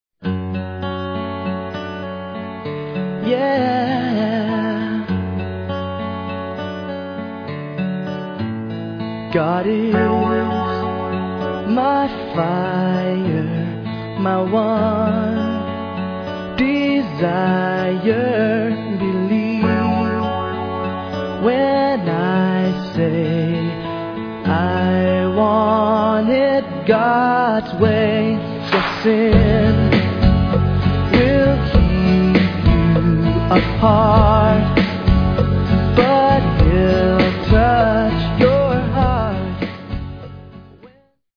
You will love the upbeat music and fun Christian message.